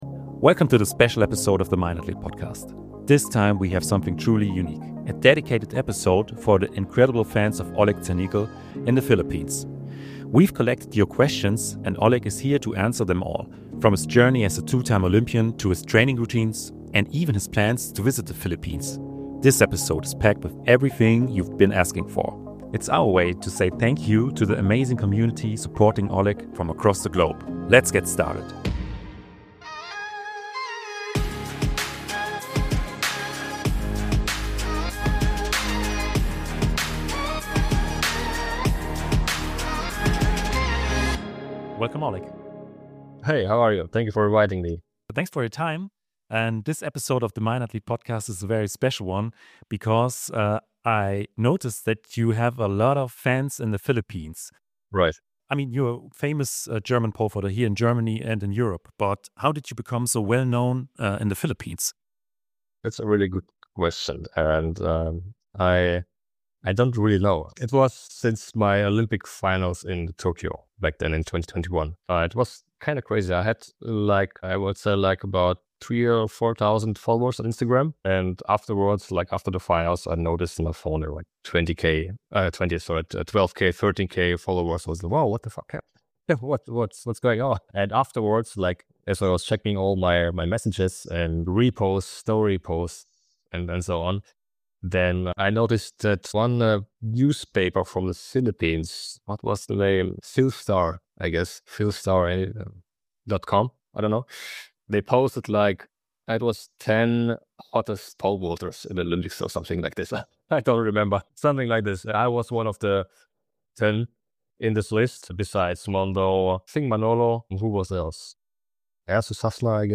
Dive into an exclusive conversation